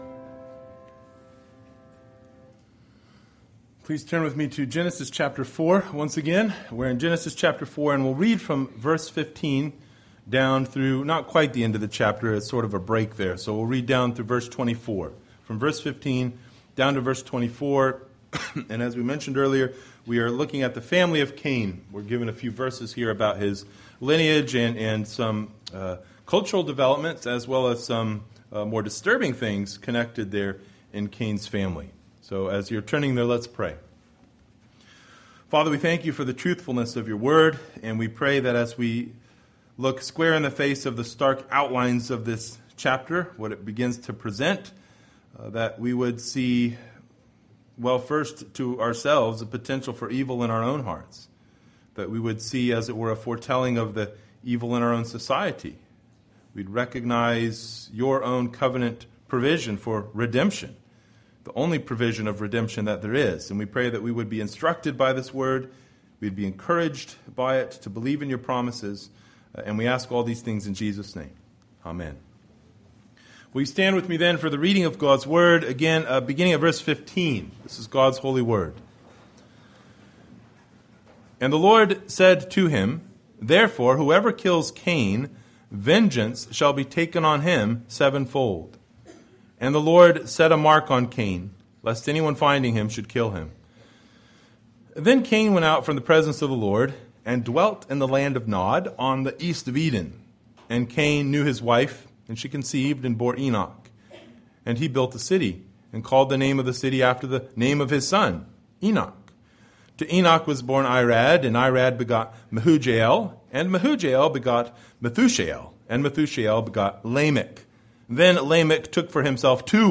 Passage: Genesis 4:15-24 Service Type: Sunday Morning